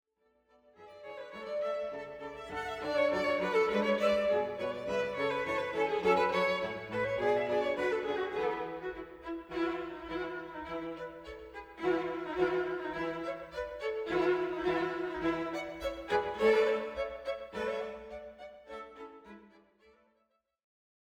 Klassiska stråkkvartetter
En stråkkvartett från Göteborg